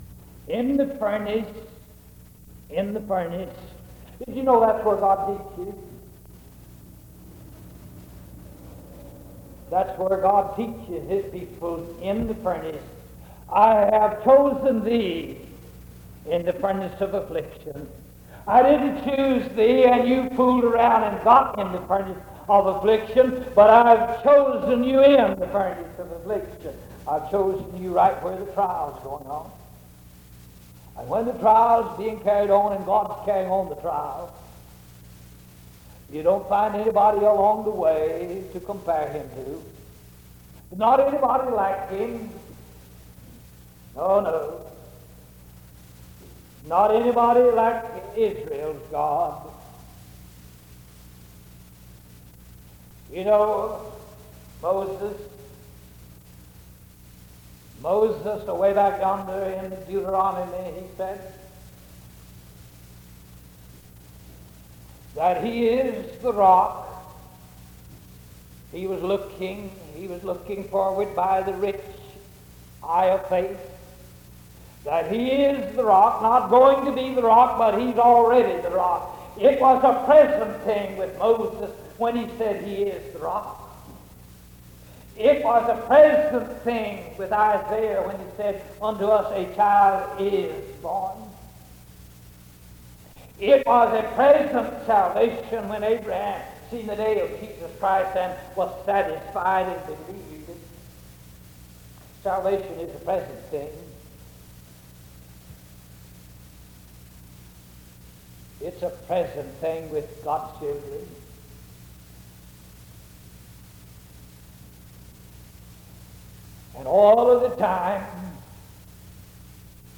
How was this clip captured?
Halcottsville (N.Y.)